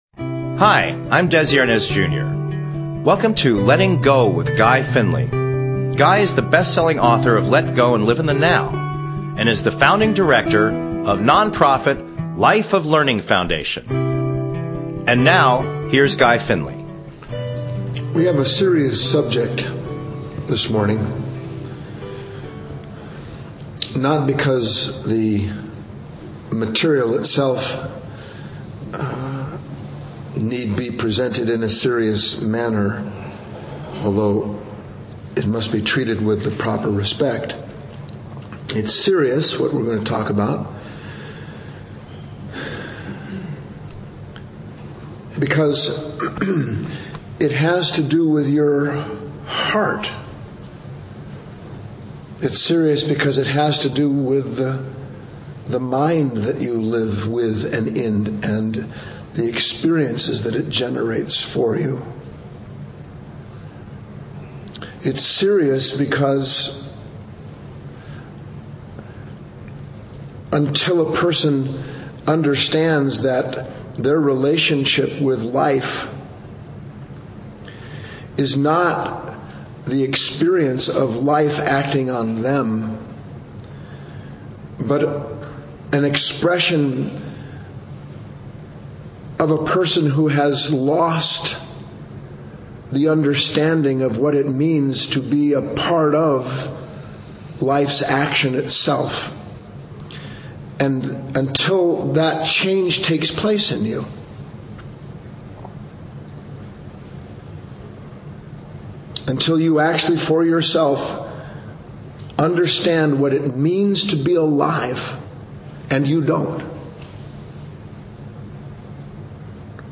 Talk Show Episode, Audio Podcast, Letting_Go_with_Guy_Finley and Courtesy of BBS Radio on , show guests , about , categorized as